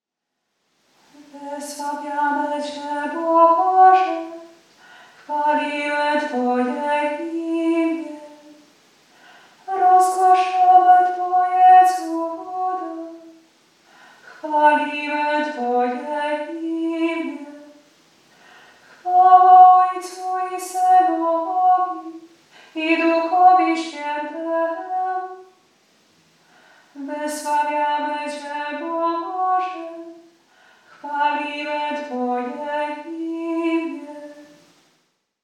Responsorium
Responsorium_ton-zwykly-kjmqmsam.mp3